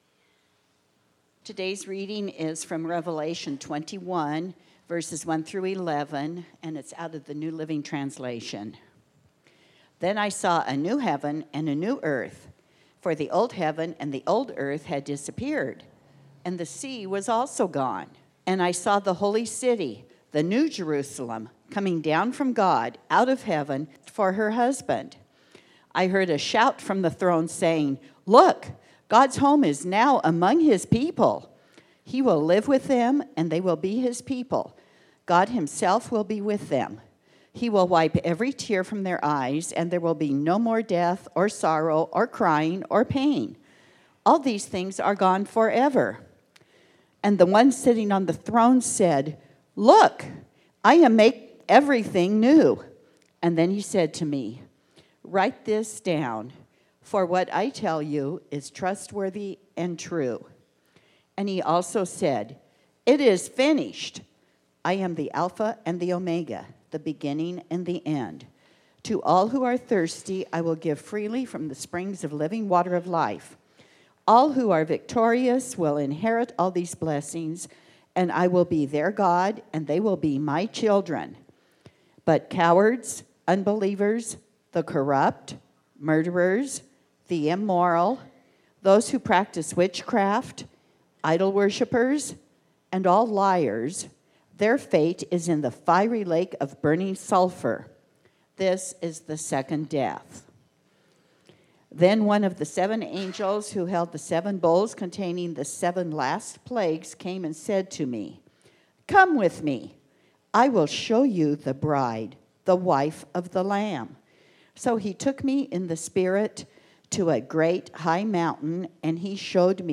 Bible Text: Revelation 22:1-11 | Preacher